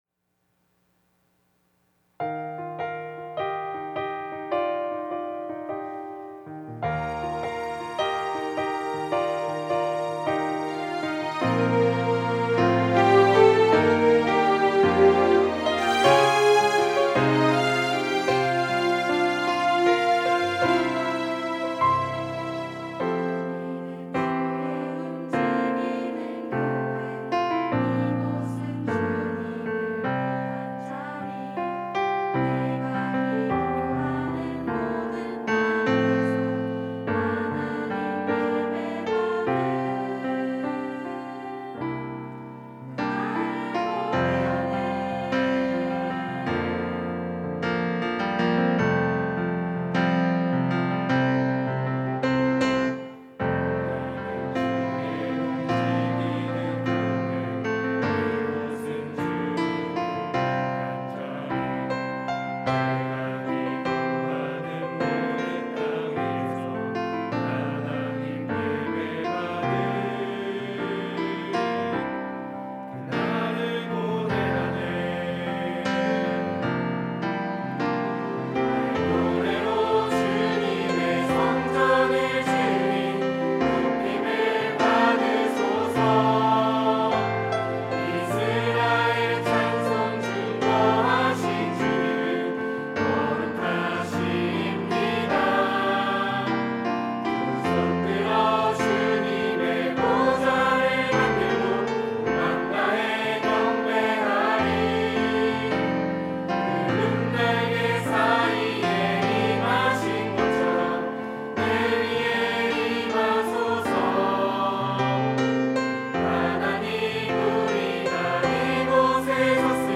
특송과 특주 - 우리는 주의 움직이는 교회
청년부 새가족 수료자